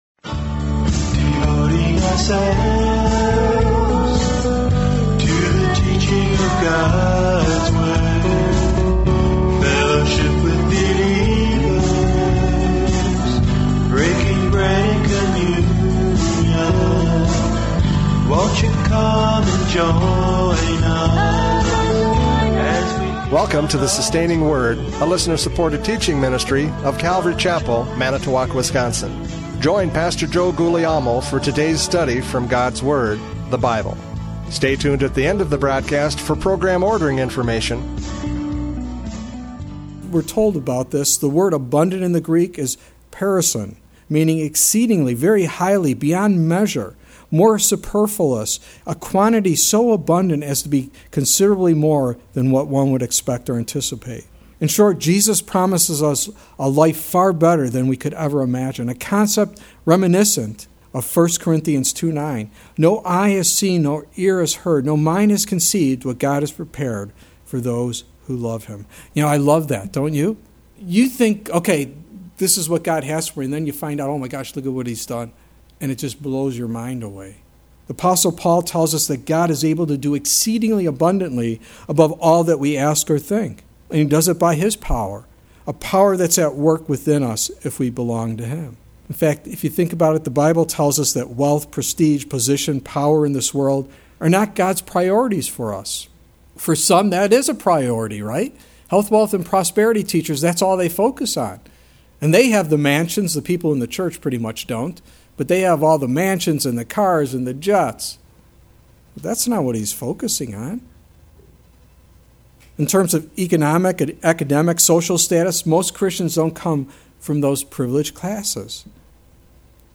John 1:4-5 Service Type: Radio Programs « John 1:4-5 The Battle of Light and Darkness!